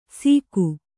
♪ sīku